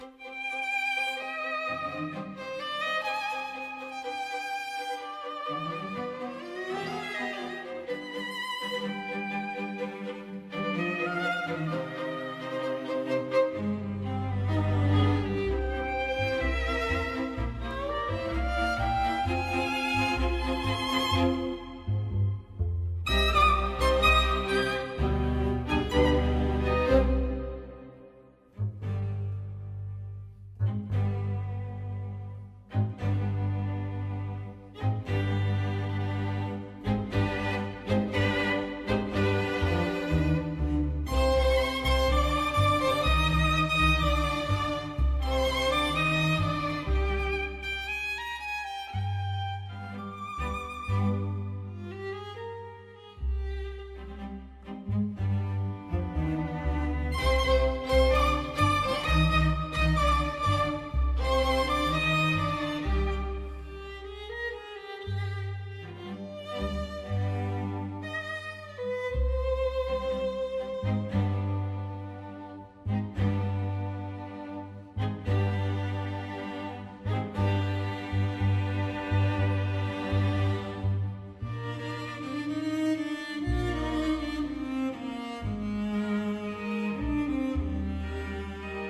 vibrantes y suntuosas melodías